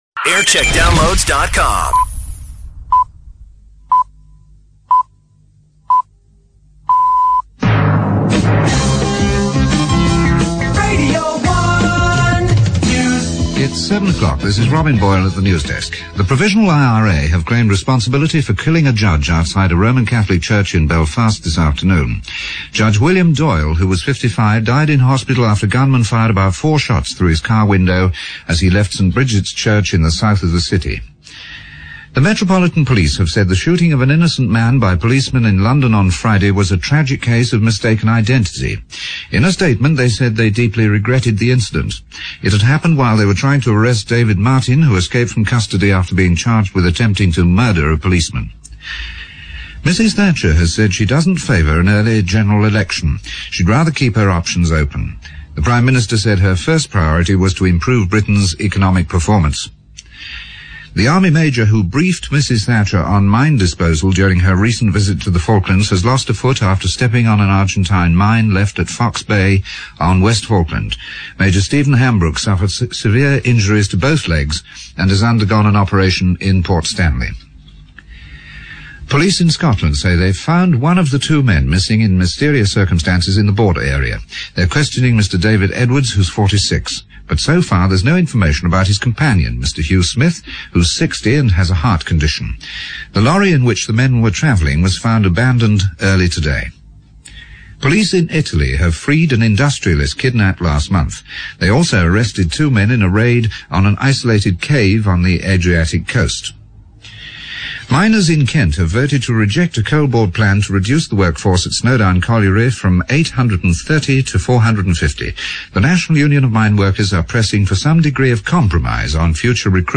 7pm news